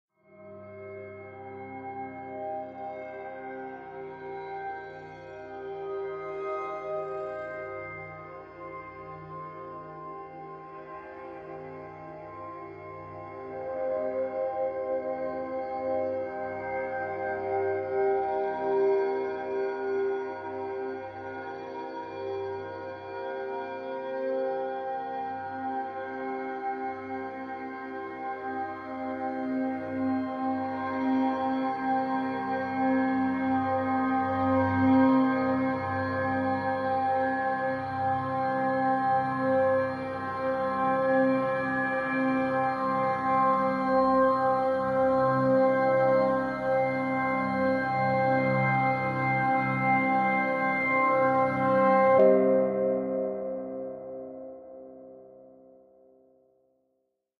Ambiance atmosphere rising fantasy